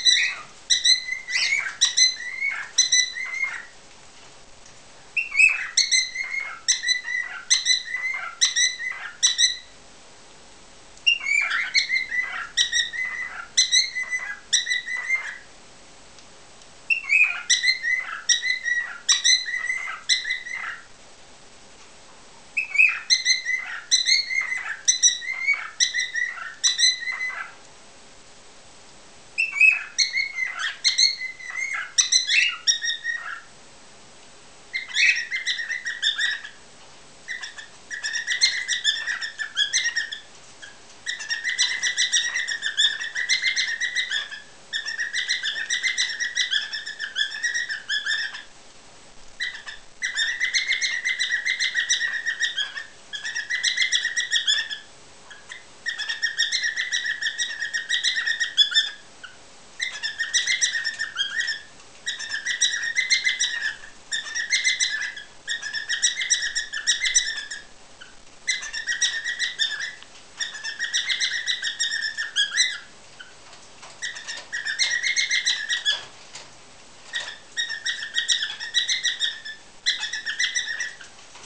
Balzende Hähne
Nymphensittichhähne können sehr unterschiedliche Gesänge entwickeln.
singenderhahn7.wav